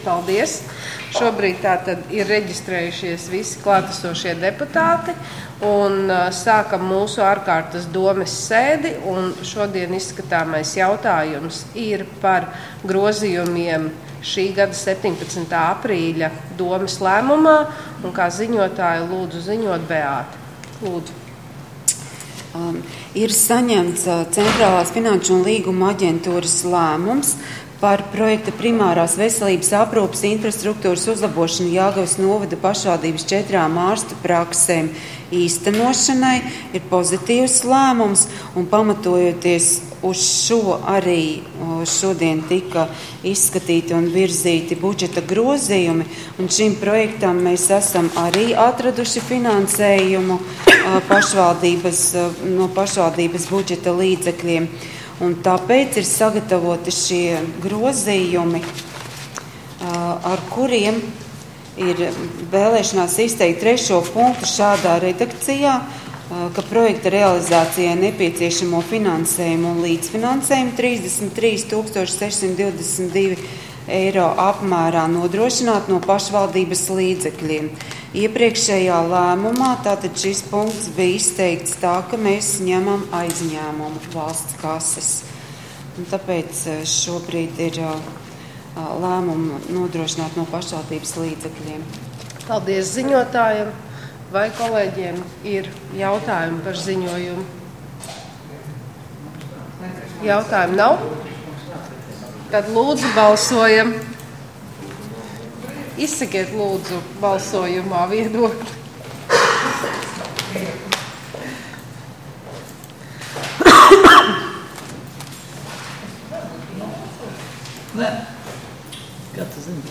Domes ārkārtas sēde Nr. 12